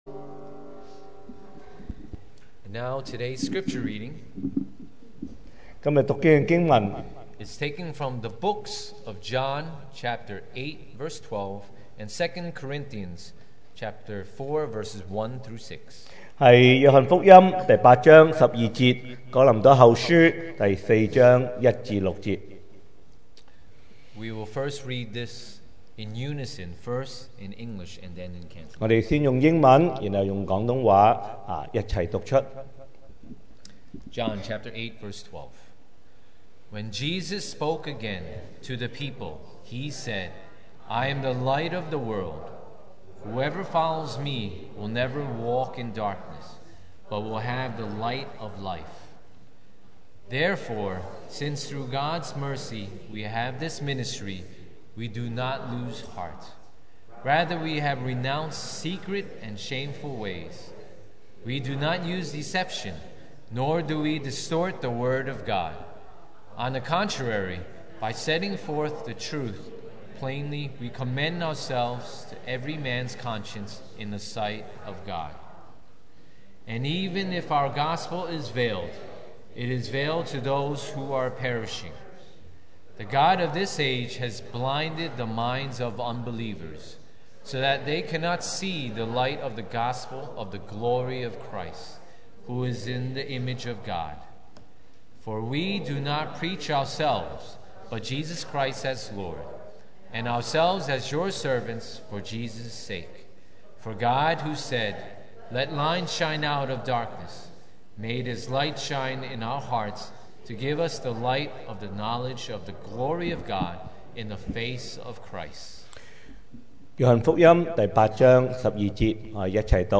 2017 sermon audios
Service Type: Sunday Morning